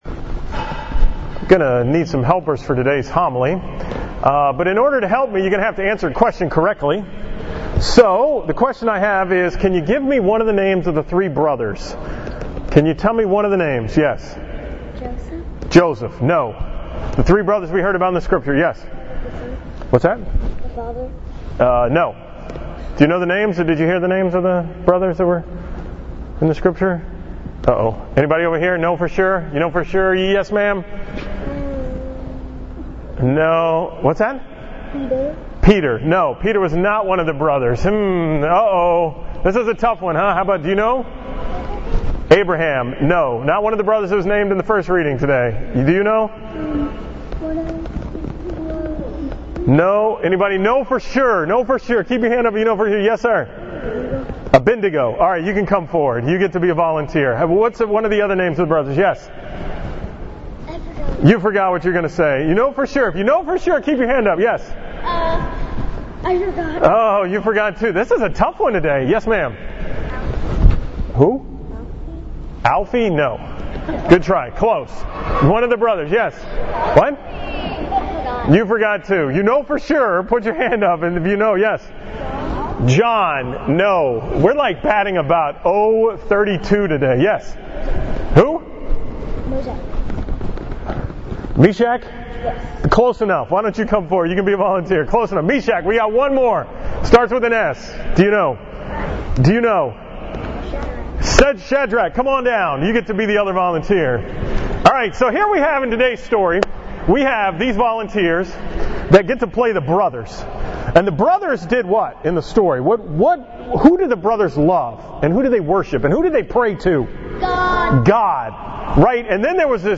From the school Mass at Christ the Redeemer on April 5, 2017